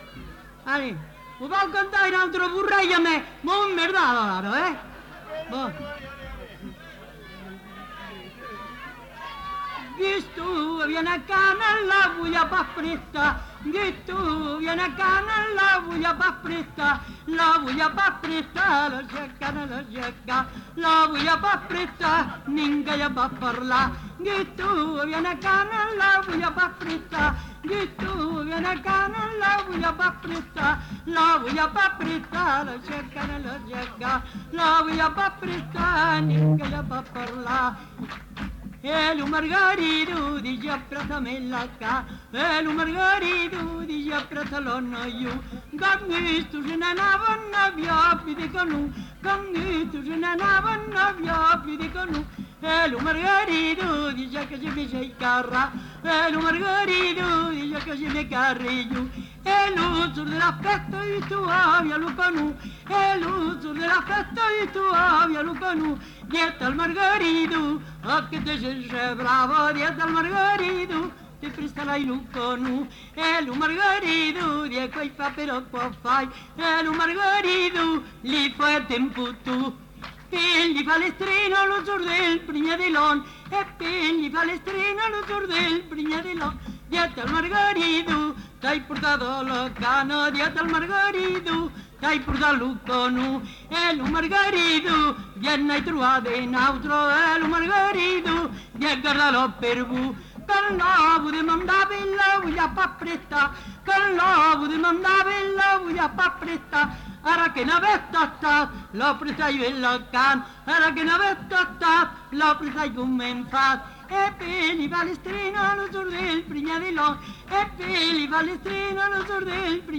Lieu : Saint-Amans-des-Cots
Genre : chant
Effectif : 1
Type de voix : voix de femme
Production du son : chanté
Danse : bourrée